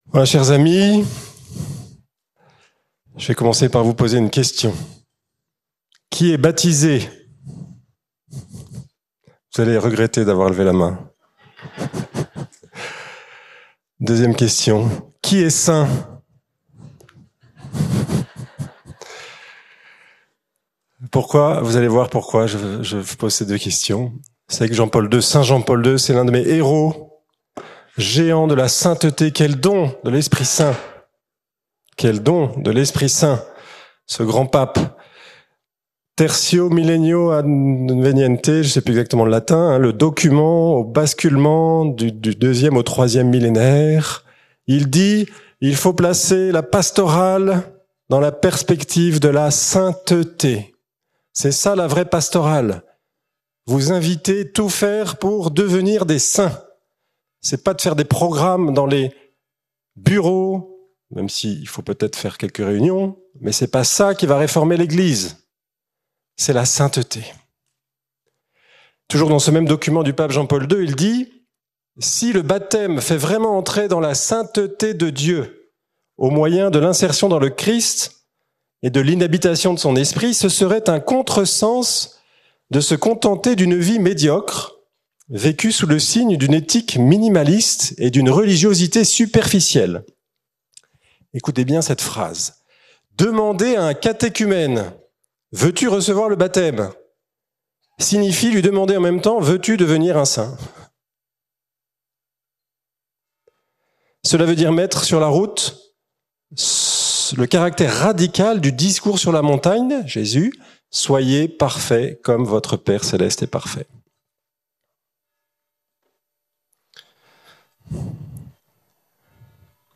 ND du Laus. Festival Marial 2024